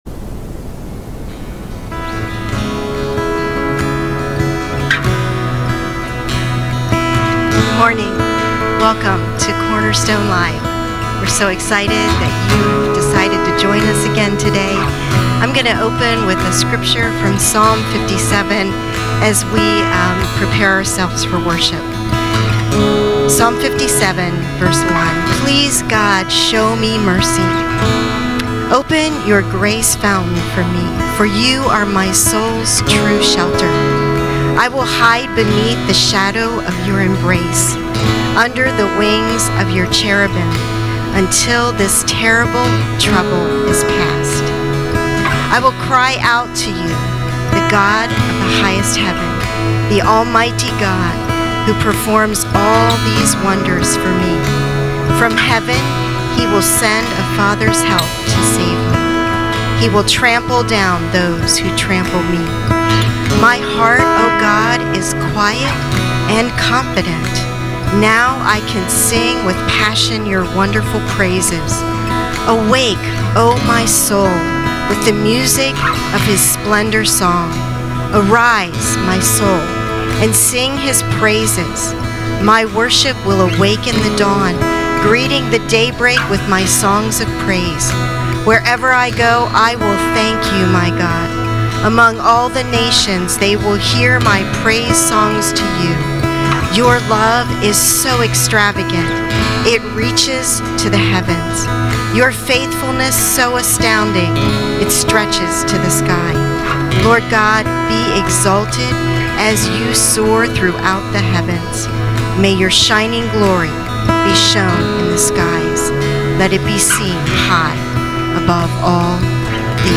Cornerstone Fellowship Sunday morning online service, April 26, 2020. This video includes worship and a timely message.